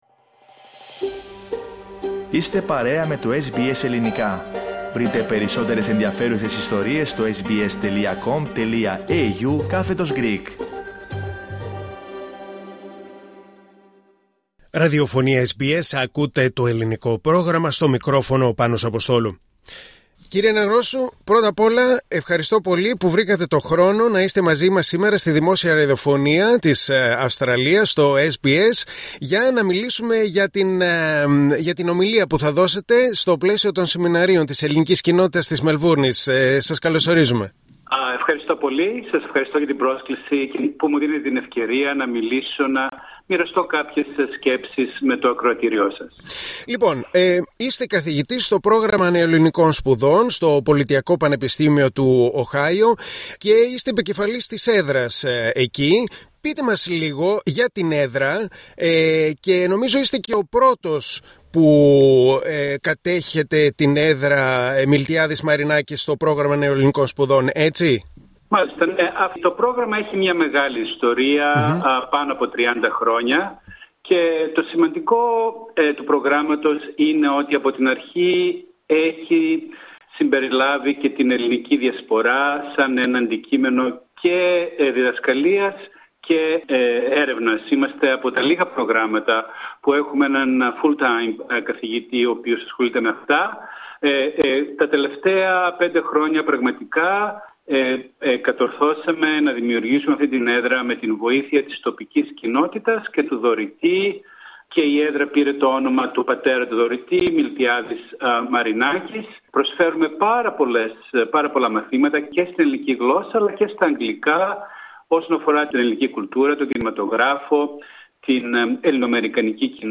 Στην διάλεξή του στην Ελληνική Κοινότητα Μελβούρνης, για θέματα διασποράς θα αναφερθεί μεταξύ άλλων, στην εκκλησία, τις παραδόσεις, τον ρατσισμό και την ελληνόμάθεια στα κοινοτικά σχολεία.